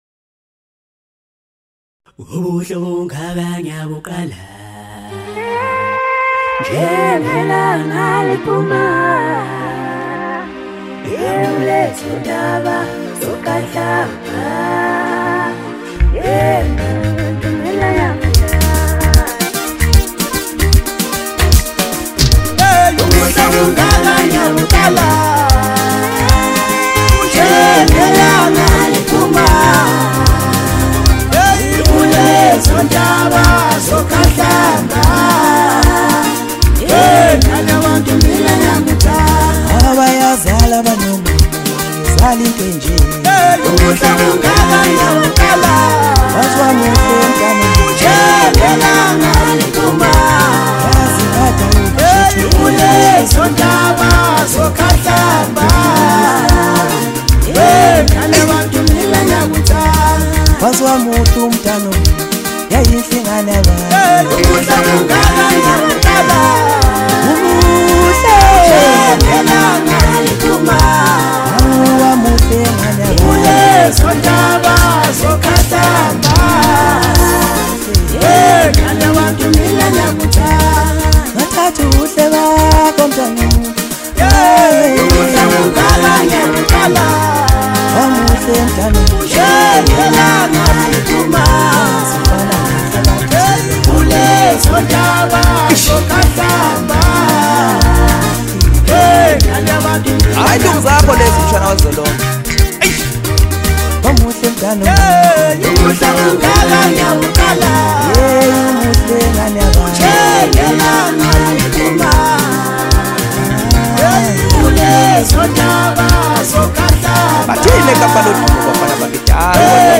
Home » Maskandi » Gqom » Lekompo